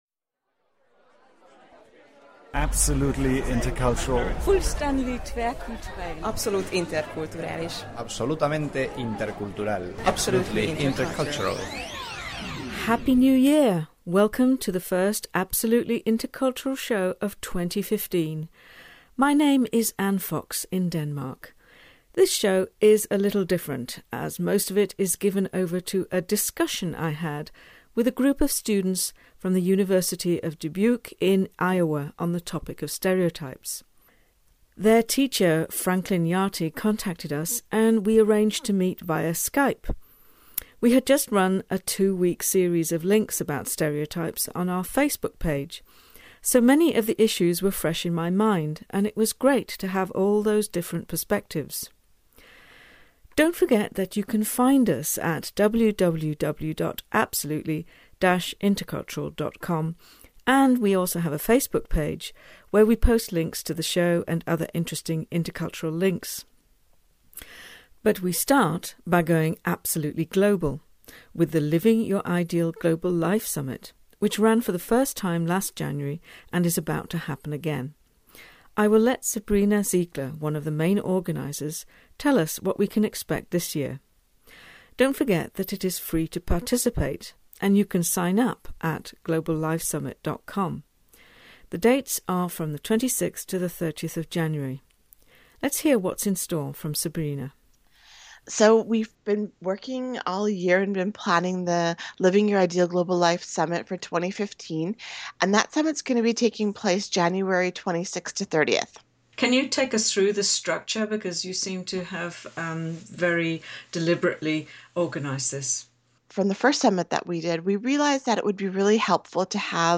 If we’re lucky we may be bringing you a few highlights from the summit in a future show. absolutely stereotypical This show is a little different as most of it is given over to a discussion I had with a group of students from the University of Dubuque in Iowa on the topic of stereotypes.